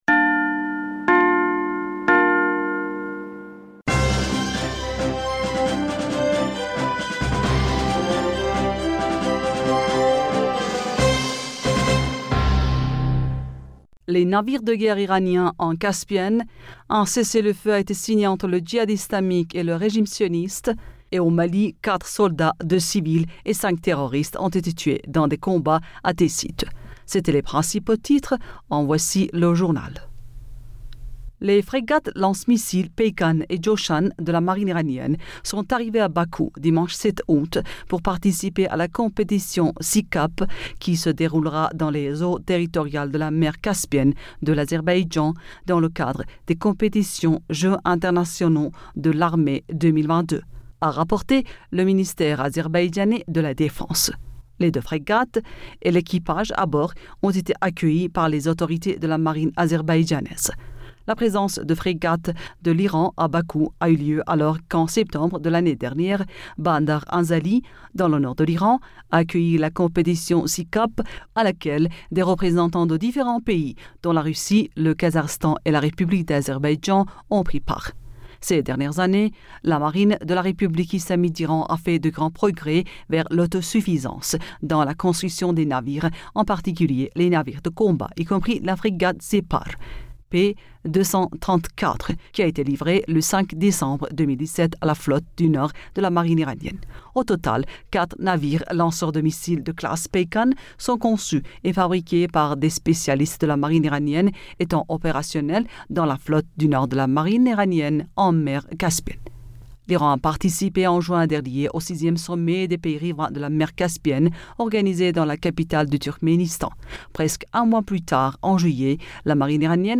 Bulletin d'information Du 08 Aoùt